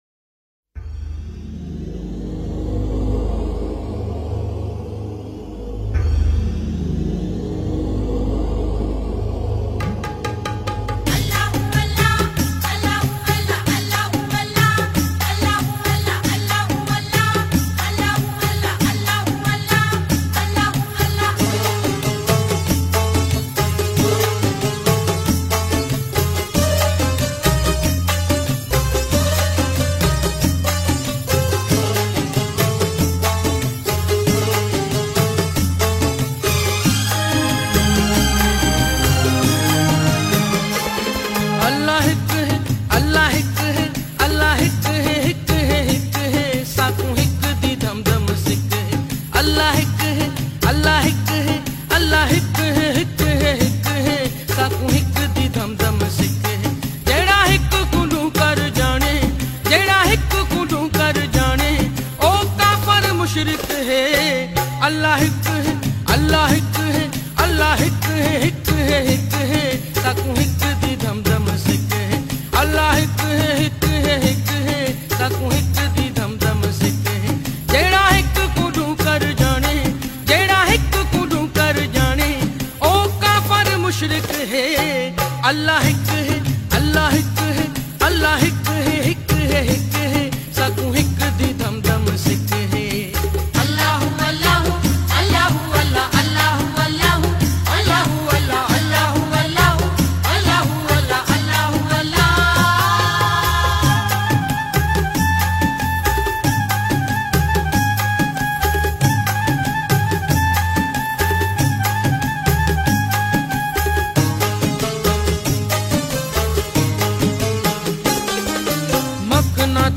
Seraiki iKalam